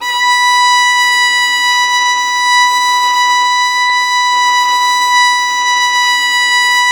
Index of /90_sSampleCDs/Roland - String Master Series/STR_Violin 4 nv/STR_Vln4 % marc